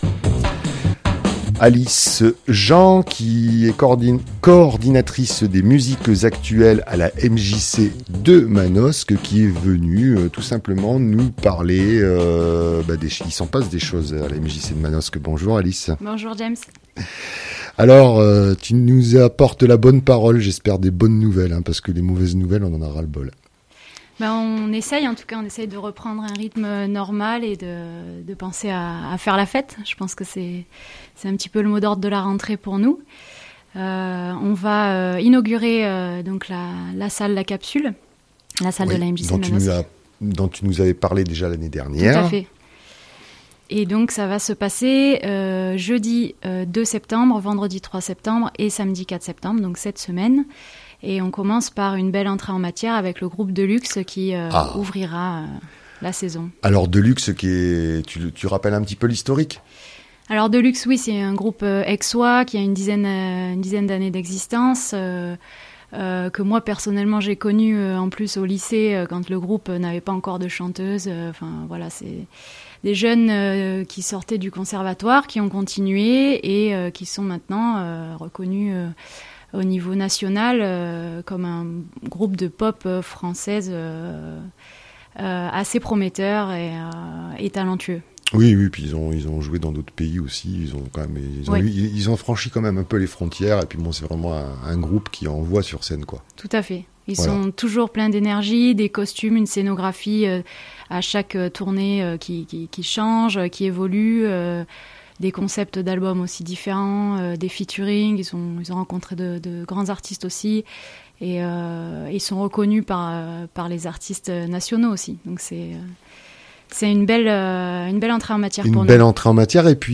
Elle nous dévoile aussi les points forts du programme du dernier trimestre 2021 avec quelques extraits musicaux qui donnent envie de danser...